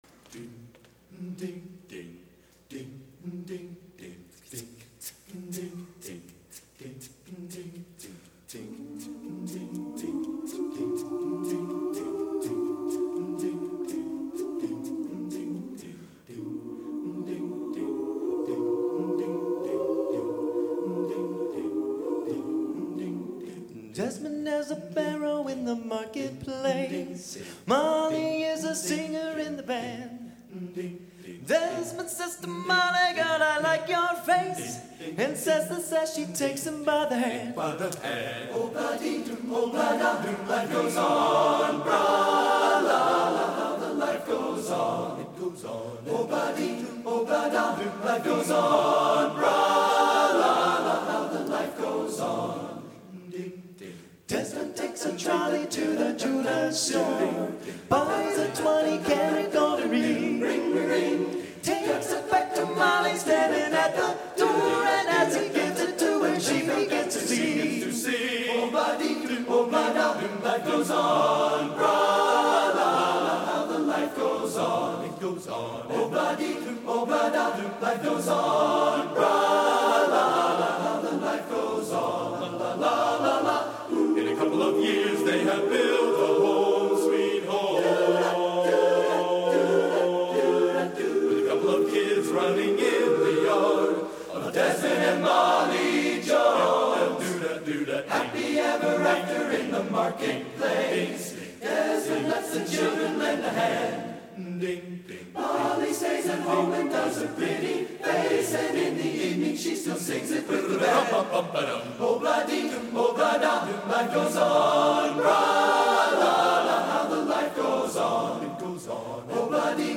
Genre: Popular / Standards | Type: End of Season